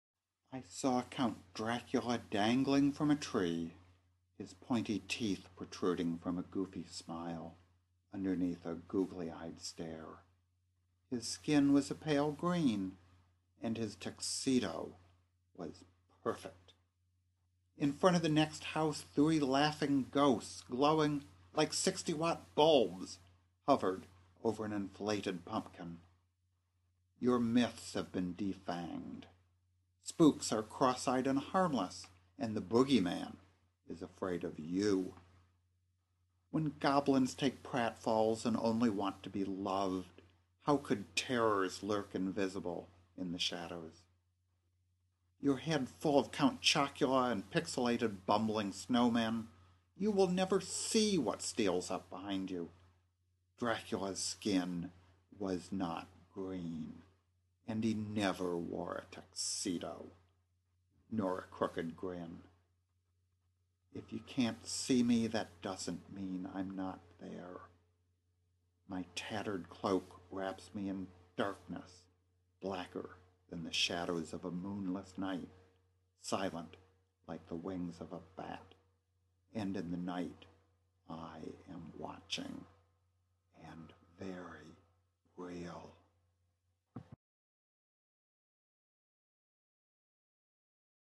2009 Halloween Poetry Reading